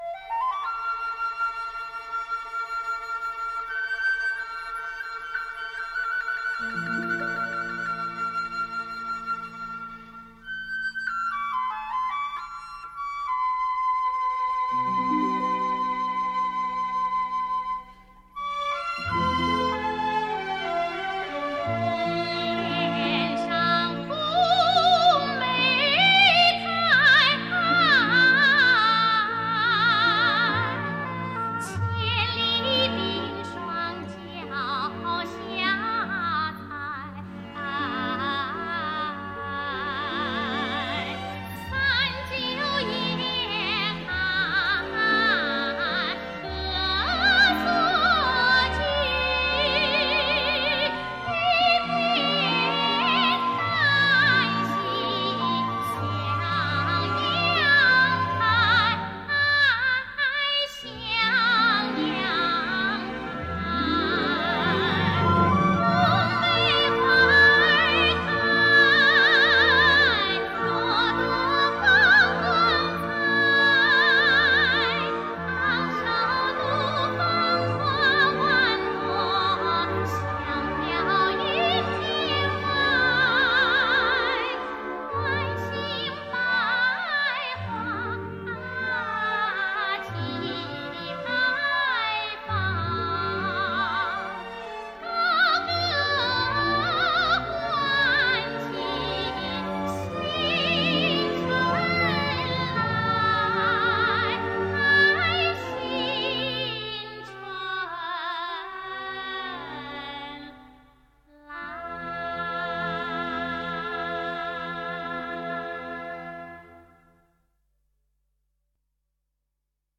原人原唱